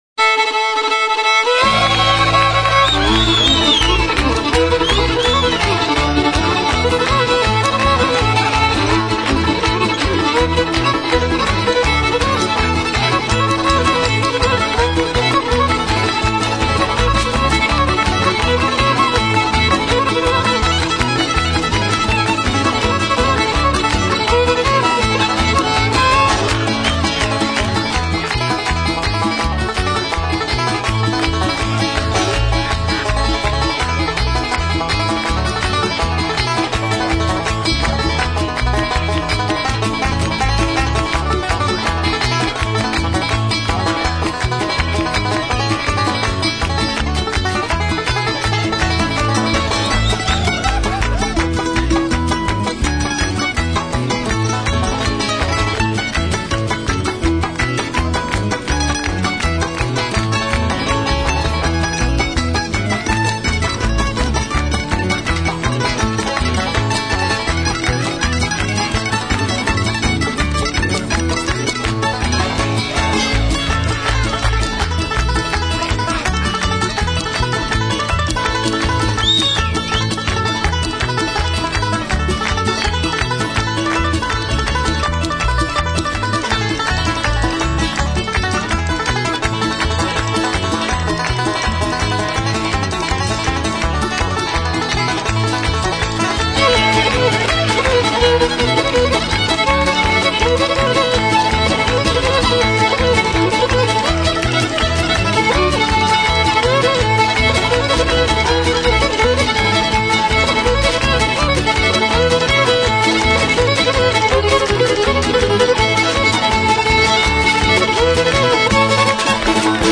Ein Instrumental Stück.
Abwechselnde Solis von Fiddle, Banjo, Mandoline, Banjo, Fiddle.
Bluegrass1.mp3